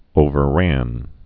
(ōvər-răn)